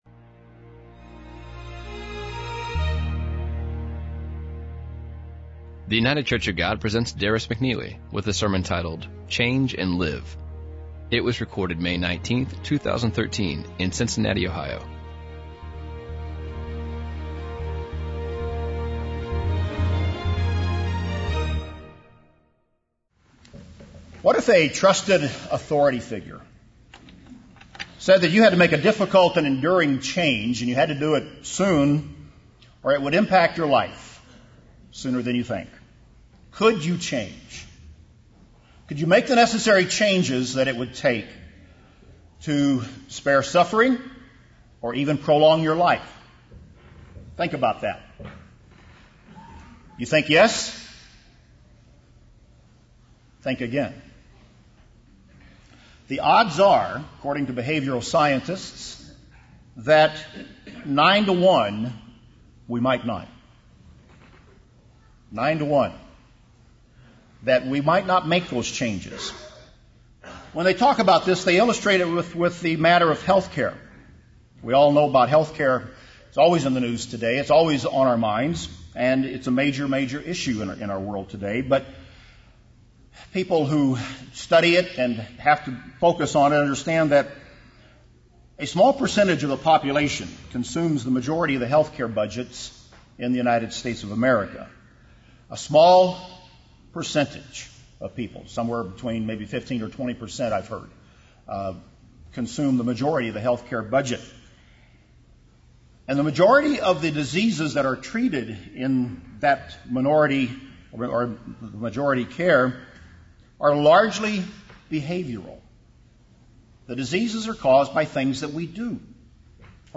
Becoming a true Christian requires change. This sermon given on Pentecost 2013 focuses on five takeaway points that the Apostle Paul wrote in Ephesians 4 on what we need to change.